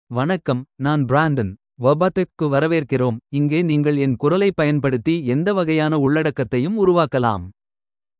Brandon — Male Tamil AI voice
Brandon is a male AI voice for Tamil (India).
Voice sample
Listen to Brandon's male Tamil voice.
Male
Brandon delivers clear pronunciation with authentic India Tamil intonation, making your content sound professionally produced.